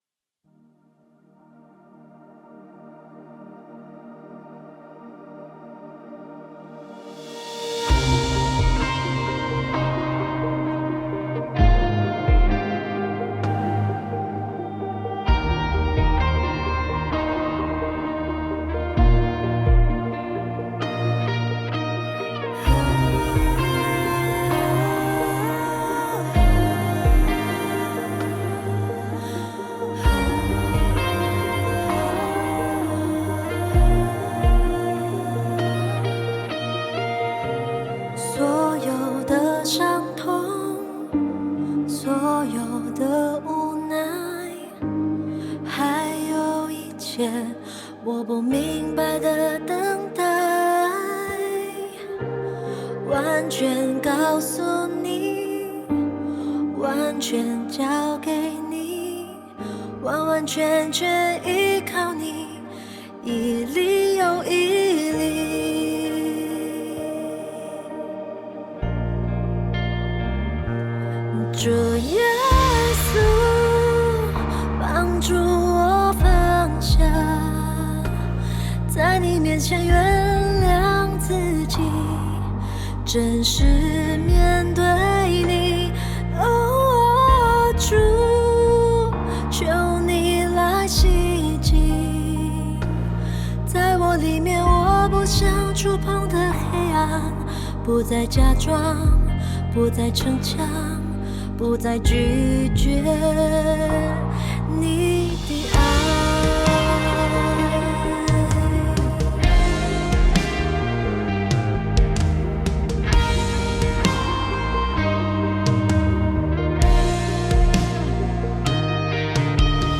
录音室 Recording Studio